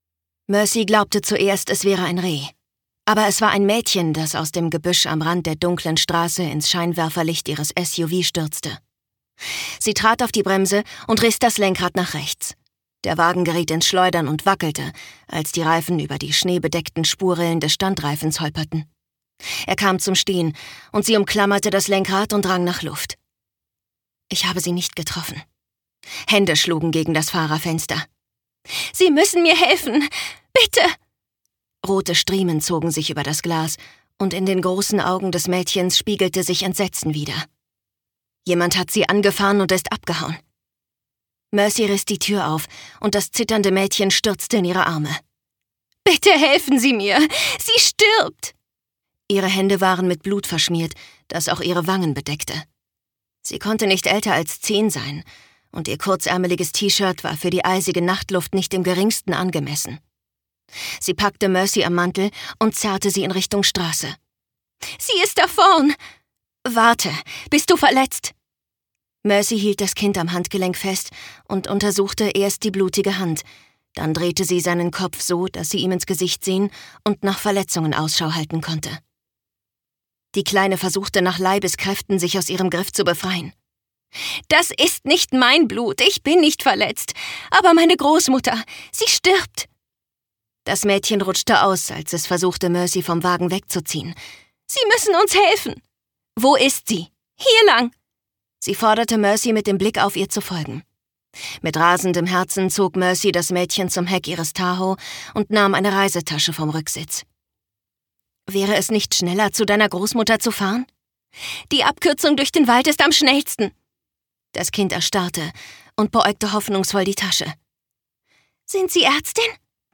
Merciful Secret – Verzeihet meine Sünden - Kendra Elliot | argon hörbuch
Gekürzt Autorisierte, d.h. von Autor:innen und / oder Verlagen freigegebene, bearbeitete Fassung.